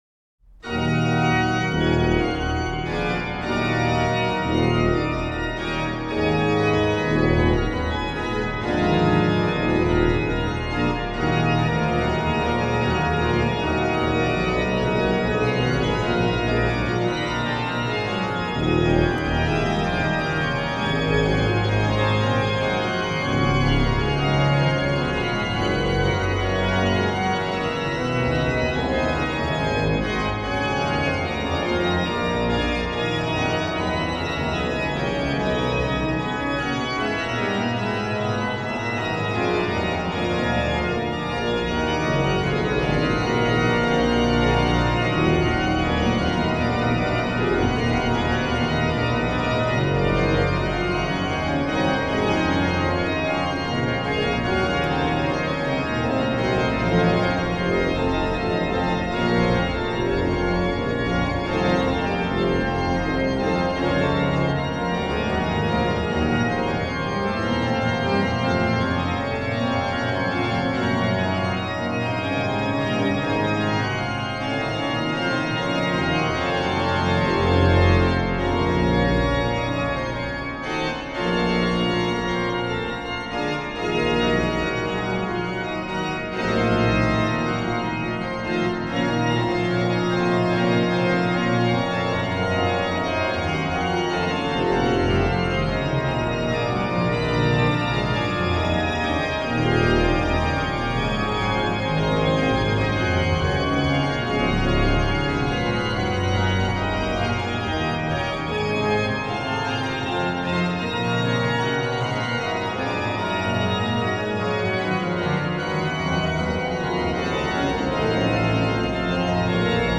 HW: Pr16, Pr8, Rfl8, Oct4, Qnt3, Oct2, Mix, Zim
Ped: Pr16, Oct8, Oct4, Mix, Pos16